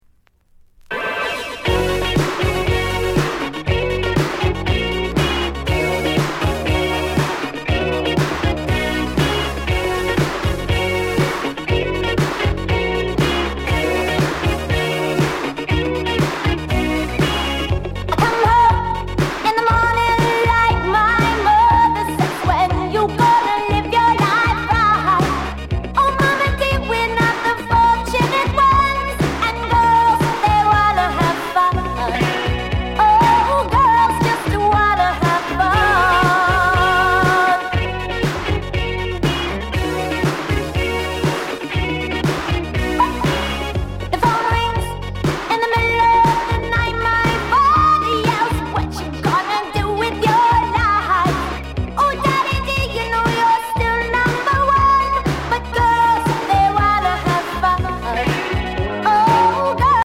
SOUND CONDITION EX-